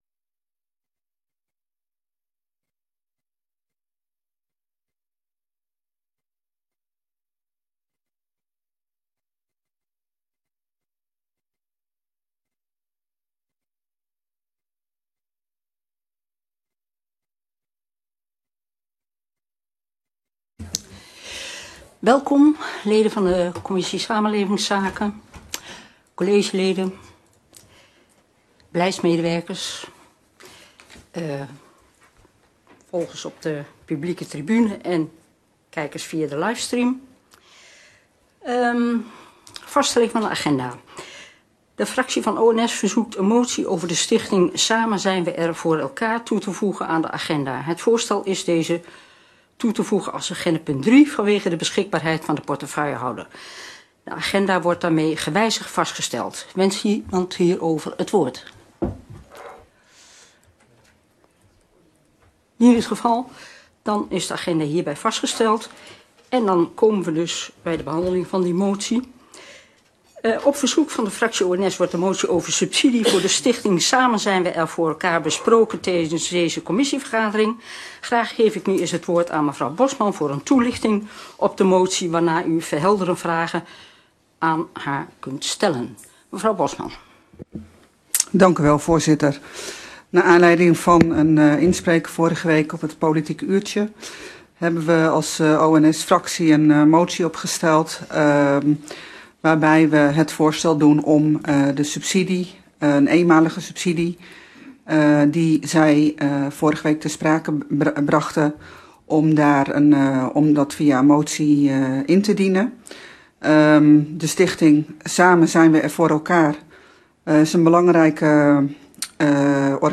Vergadering van de commissie Samenlevingszaken op maandag 28 november 2022, om 19.30 uur, fysiek in kamer 63 van het gemeentehuis.